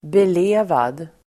Ladda ner uttalet
Uttal: [bel'e:vad]